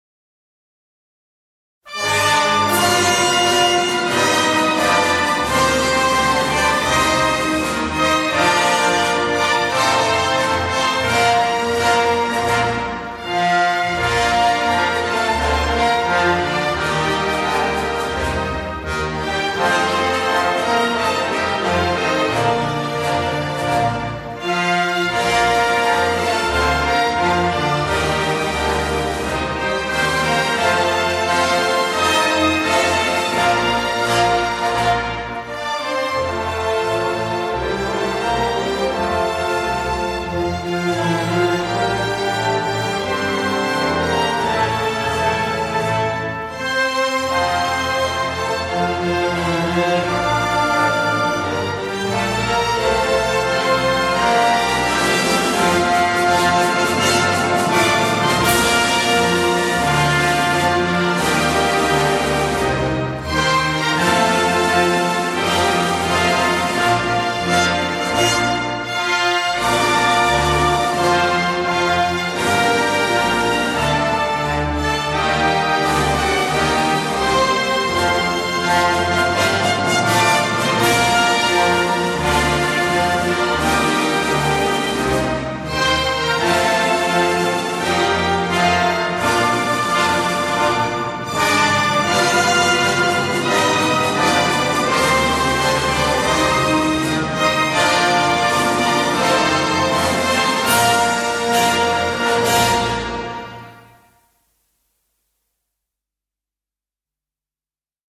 国际歌（仪式演奏版）
国际歌(管弦乐合奏).mp3